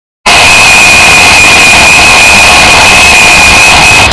Alarm
Alarm.wav